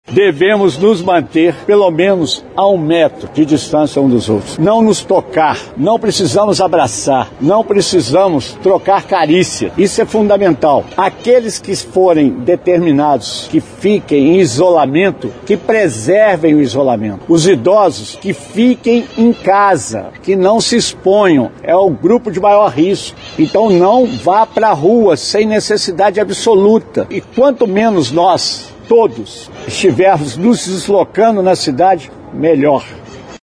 Em coletiva nesta tarde de segunda-feira, 16, o prefeito Antônio Almas (PSDB) anunciou a suspensão das aulas na rede municipal de ensino e eventos realizados pela prefeitura para mais de 100 pessoas.